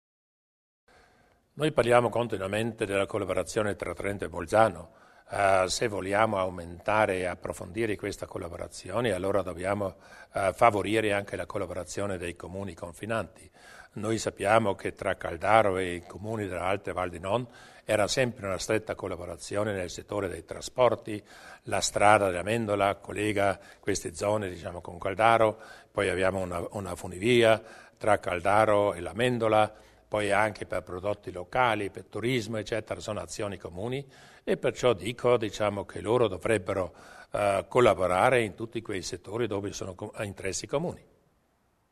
Il Presidente Durwalder illustra l'accordo tra Caldaro ed i Comuni della Val di Non.